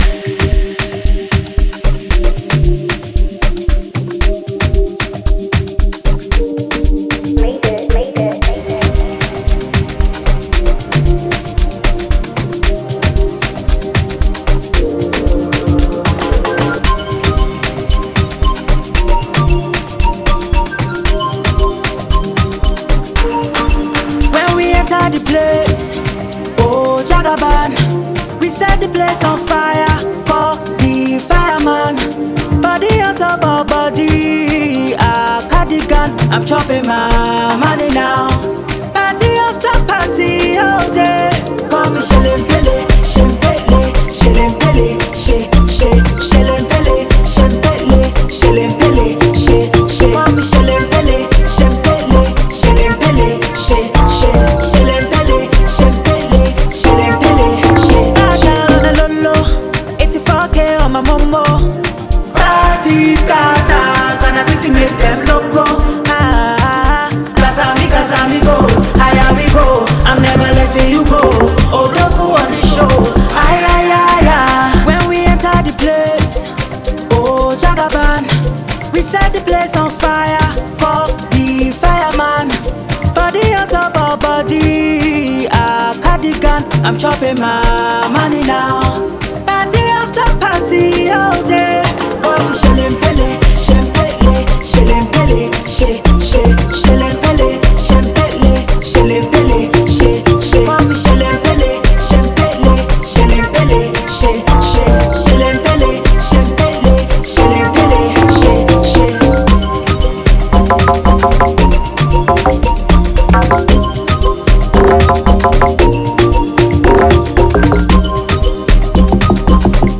amapiano-like song